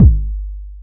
kick_timbo.wav